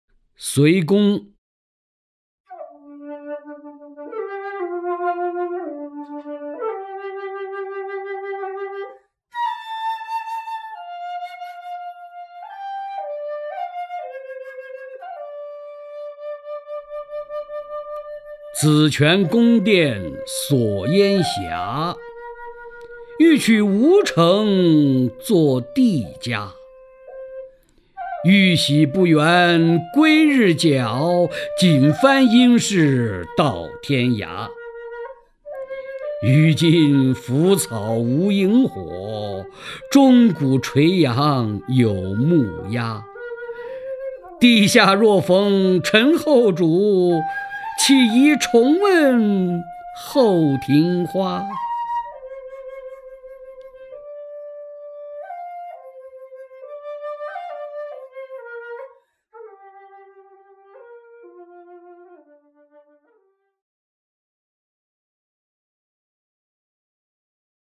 首页 视听 名家朗诵欣赏 曹灿
曹灿朗诵：《七言律诗·隋宫》(（唐）李商隐)